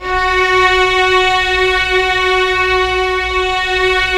Index of /90_sSampleCDs/Roland LCDP13 String Sections/STR_Symphonic/STR_Symph. Slow